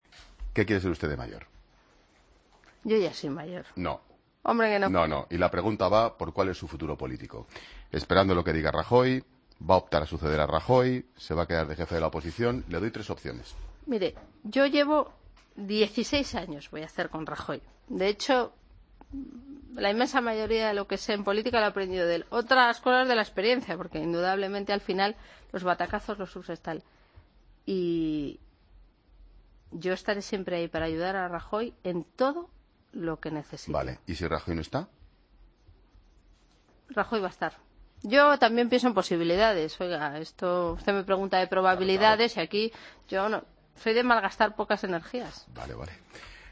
Escucha a la entonces Vicepresidenta del Gobierno en funciones, Soraya Sáenz de Santamaría, hablar sobre su futuro político en 'La Tarde' el pasado 4 de febrero de 2016